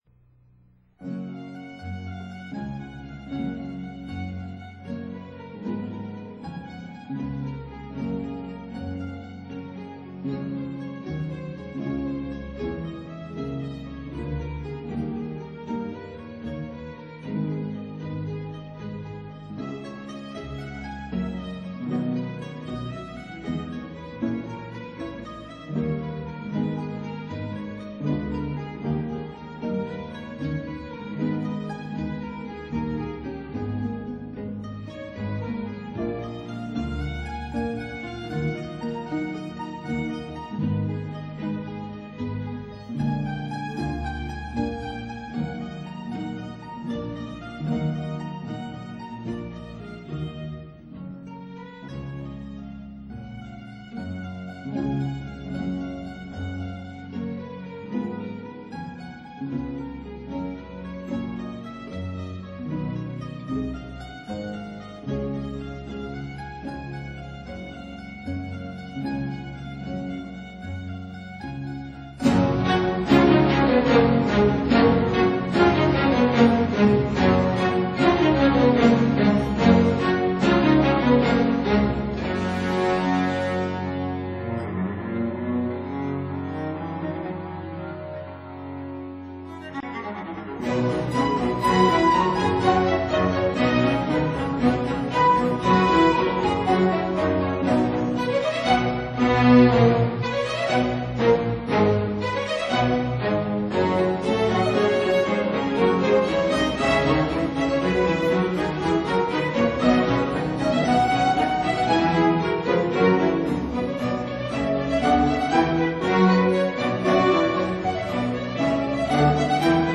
•        Concerto for 2 mandolins in G major, RV 532
•        Concerto for mandolin in C major, RV 425